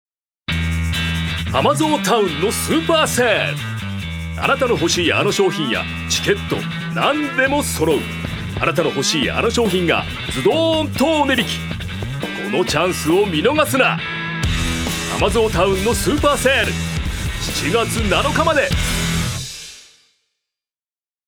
所属：男性タレント
4. ナレーション４